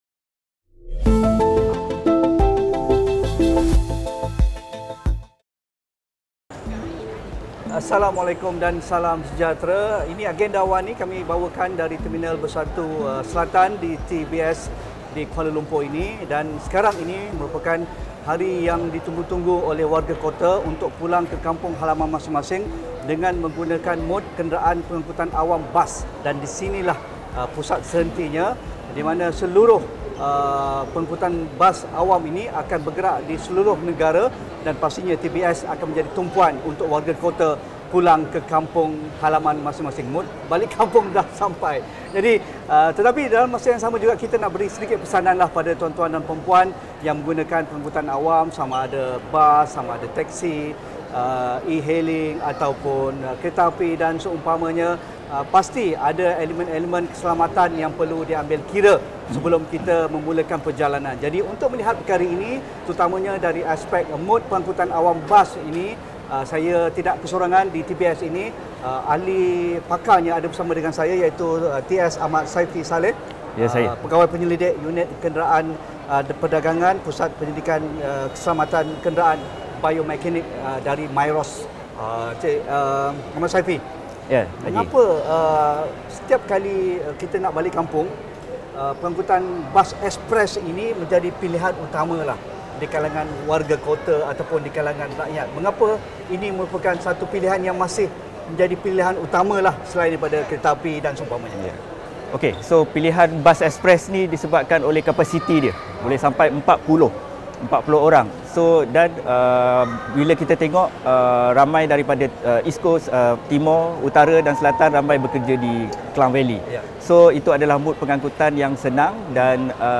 Teruja untuk merancang perjalanan pulang sempena menjelang sambutan Aidilfitri, apa aspek keselamatan yang perlu diberi perhatian bagi elak berlakunya sebarang risiko insiden yang tidak diingini? Diskusi 8.30 malam.